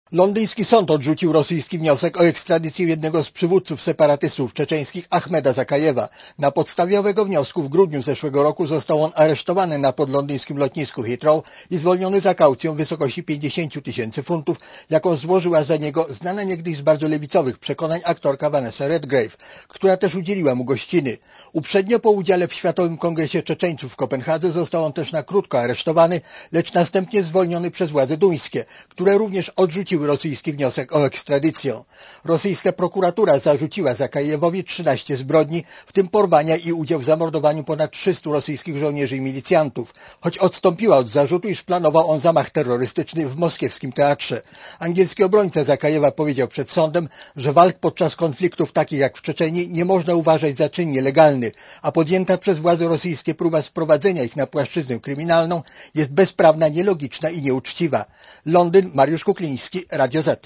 Korespondencja z Londynu (236Kb)